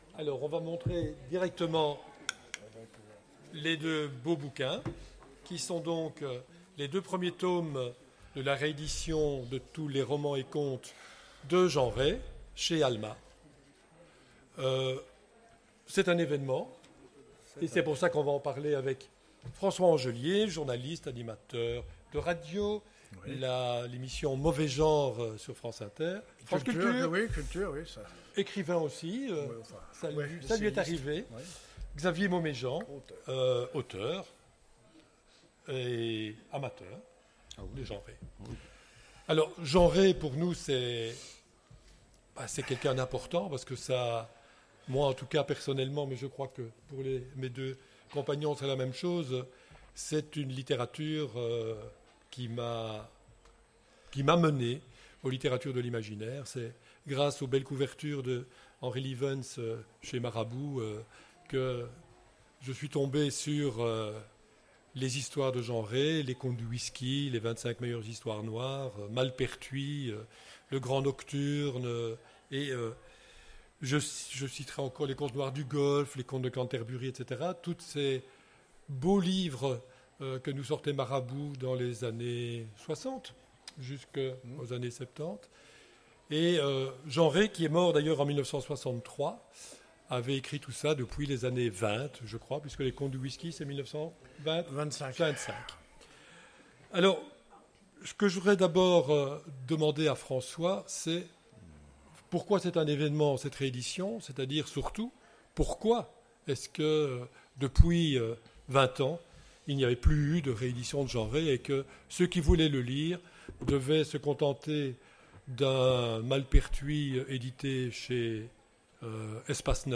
Imaginales 2016 : Conférence Rééditer les romans et contes…